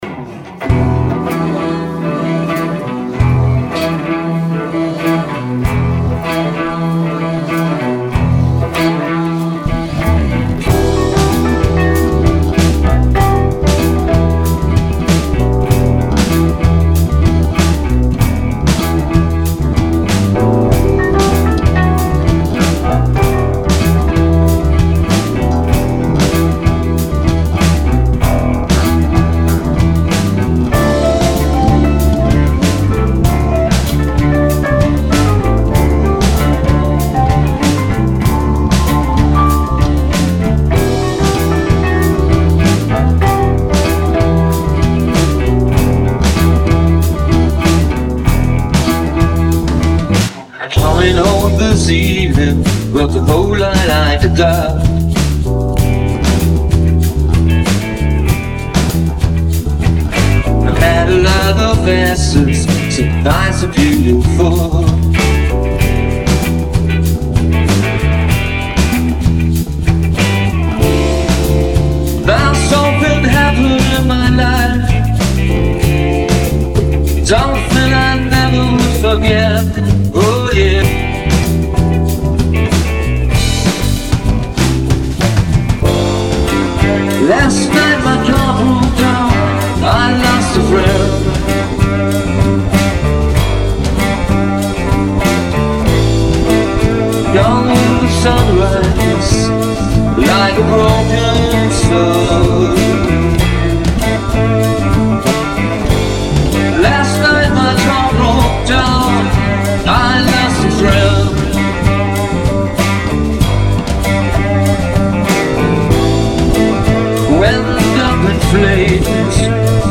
Es gibt von dem Stück etwa sechs Stereo-Aufnahmen aus der Zeit von Okt.2010 bis April 2011, mit verschiedenen Arrangements, keine davon wirklich perfekt gespielt. Mit Hilfe moderner De-Mix-Tools gelang es mir, diese Version draus zu kreieren.
Occasional Alto Sax
Guitar
Bass, add.Keys